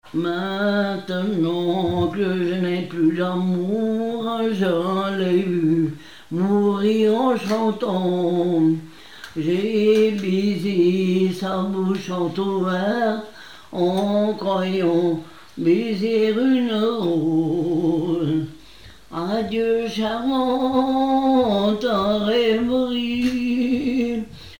Localisation Mieussy
Pièce musicale inédite